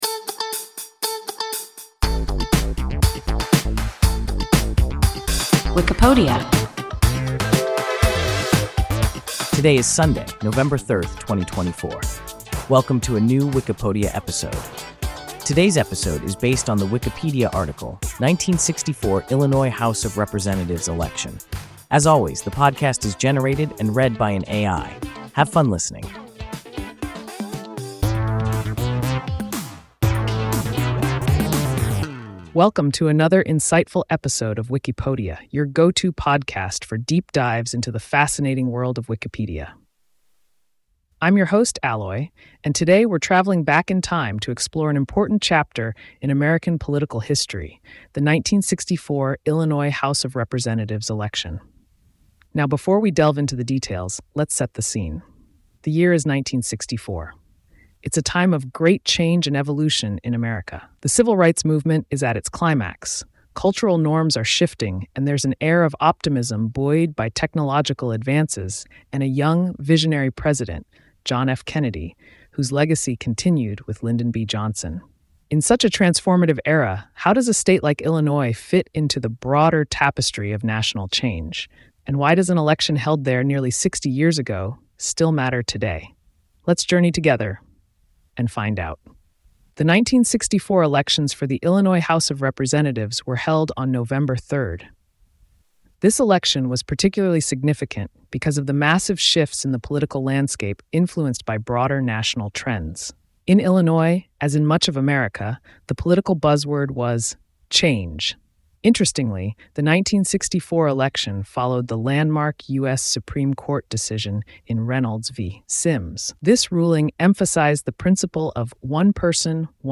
1964 Illinois House of Representatives election – WIKIPODIA – ein KI Podcast